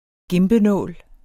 Udtale [ ˈgembəˌnɔˀl ]